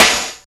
47.01 SNR.wav